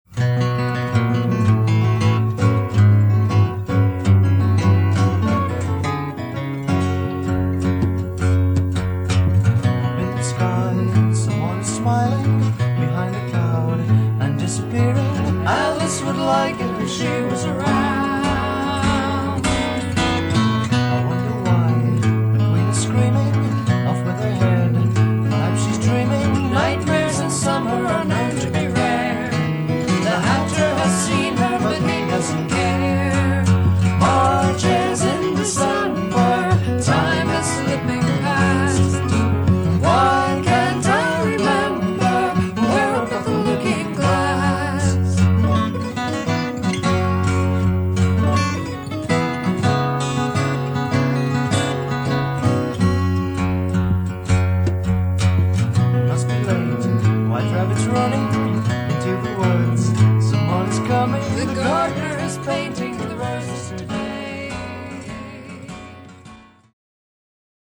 Recorded 1971 in San Francisco and Cambria, USA
remastered from the original tapes